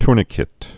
(trnĭ-kĭt, tûr-)